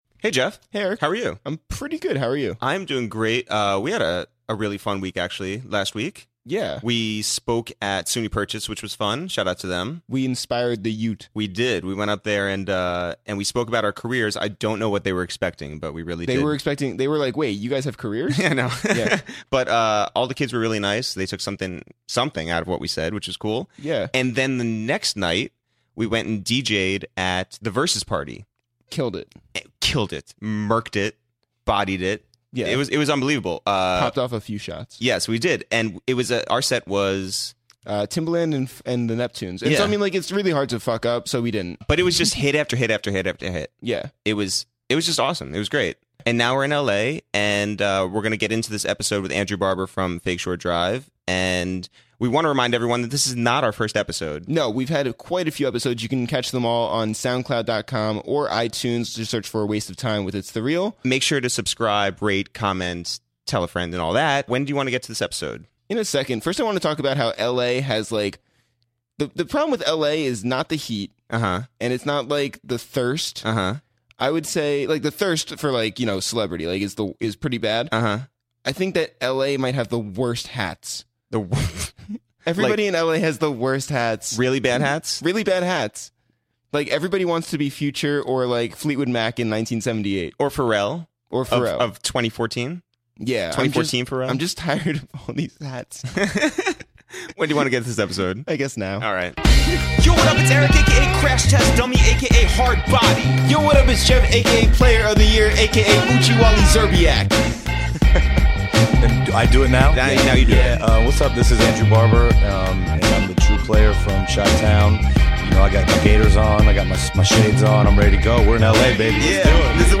to the studio in Los Angeles